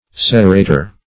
serrator - definition of serrator - synonyms, pronunciation, spelling from Free Dictionary Search Result for " serrator" : The Collaborative International Dictionary of English v.0.48: Serrator \Ser*ra"tor\, n. [NL.]